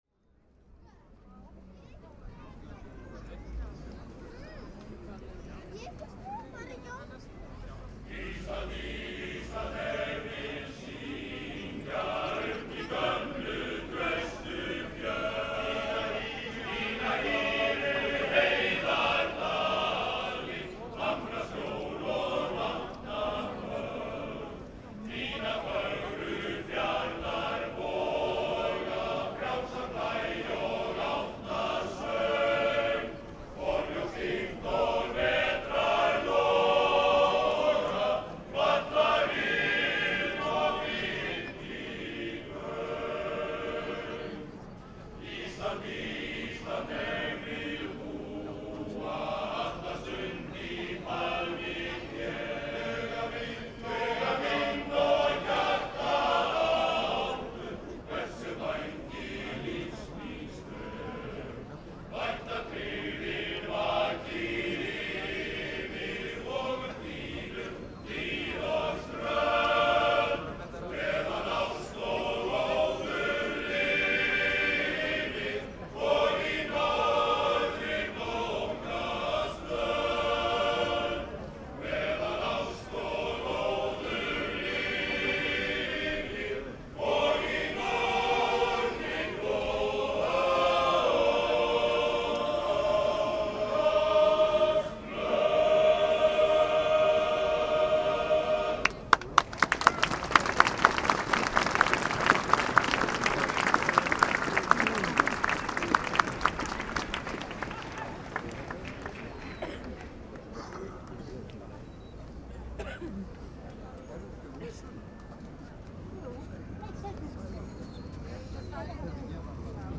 Every 1st of May is a Labour day parade in Reykjavik, where people walk together to the city center, listening to speeches and music. For one year ago I walked this parade and listening to the day’s program with binaural microphones.
Ég bjóst ekki við neinu spennandi en lét upptökutækið þó ganga allan tíman. Þegar opinberum ræðuhöldum á Hallærisplaninu lauk tók við söngur Karlakór Reykjavíkur og Léttsveitarinnar. Þar á eftir tók við samsöngur á Internationalinum.